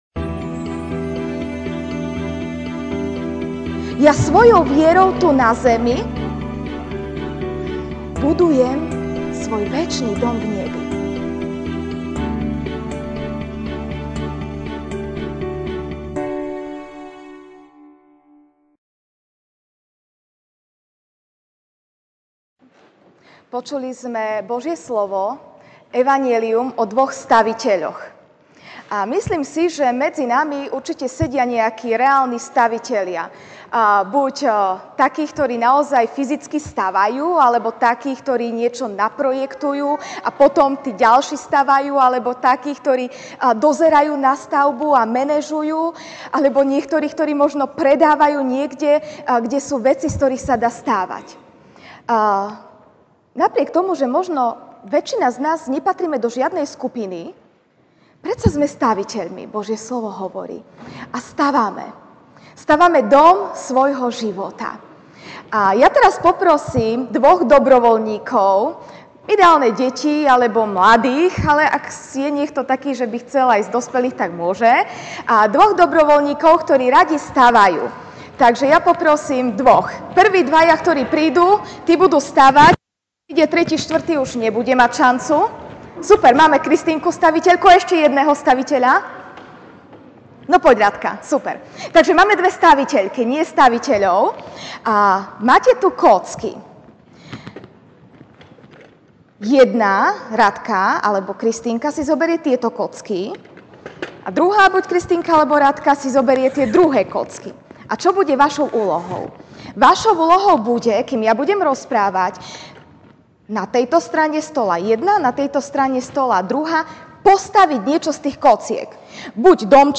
Ranná kázeň